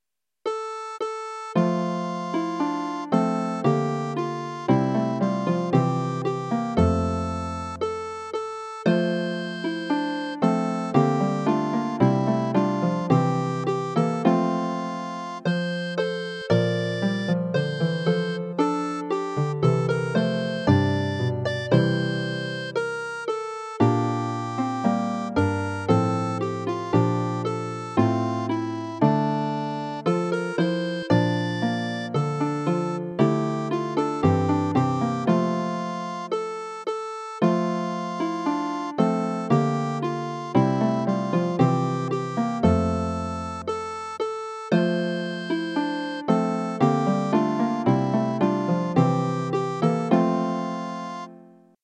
Voice and keyboard or harp